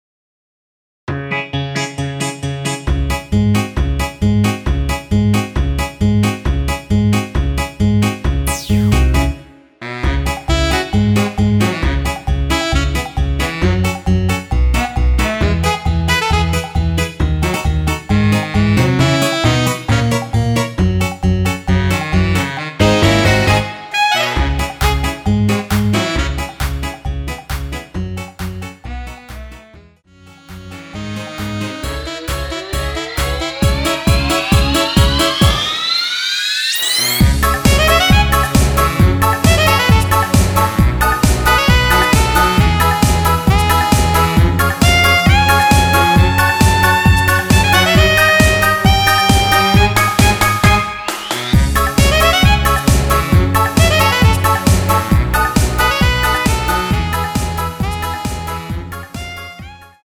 원키에서(-5)내린 MR입니다.
C#m
앞부분30초, 뒷부분30초씩 편집해서 올려 드리고 있습니다.